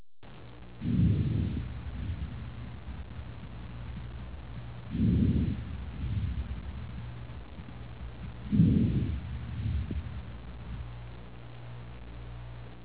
Normal-Vesicular.mp3